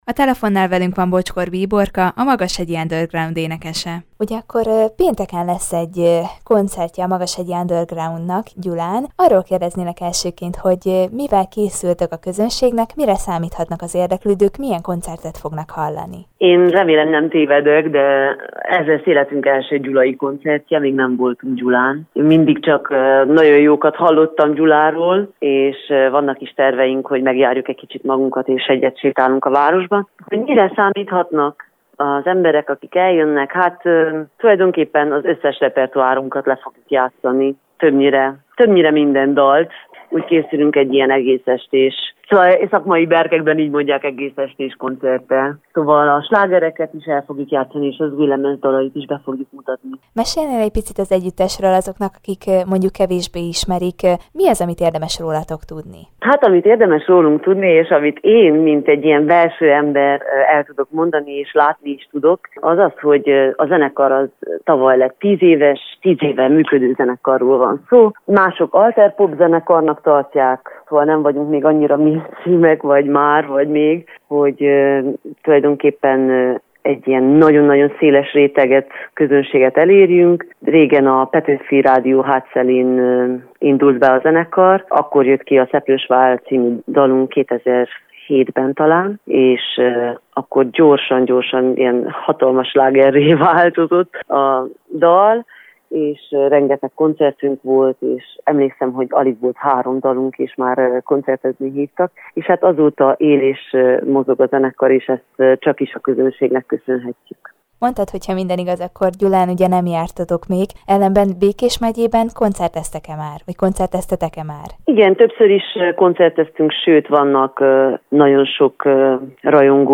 Bocskor Bíborkával, a Magashegyi Underground énekesével beszélgetett tudósítónk az együttes indulásáról és az első gyulai koncertjükről.